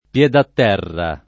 vai all'elenco alfabetico delle voci ingrandisci il carattere 100% rimpicciolisci il carattere stampa invia tramite posta elettronica codividi su Facebook pied-à-terre [fr. p L et a t $ er ] s. m.; inv. — talvolta italianizz. in piedatterra [ p LH datt $ rra ] (err. piedaterra ), inv.